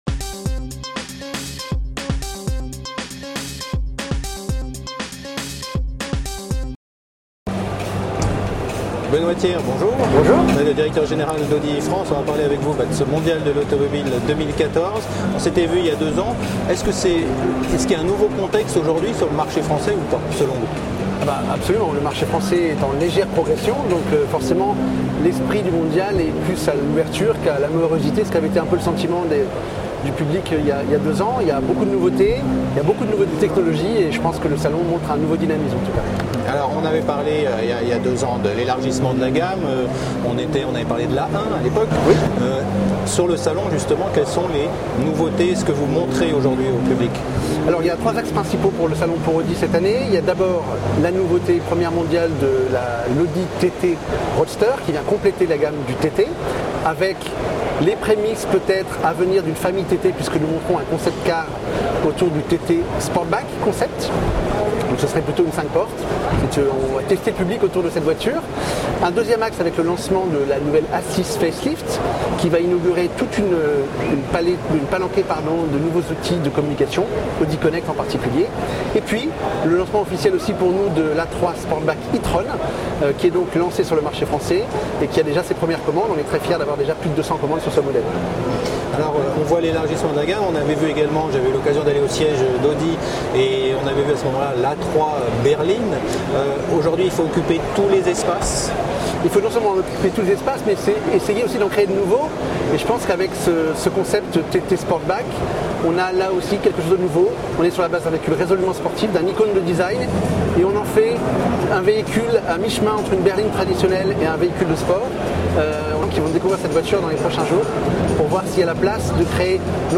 Au Mondial 2014 la Web Tv rencontre les dirigeants des grands groupes automobiles présents en France.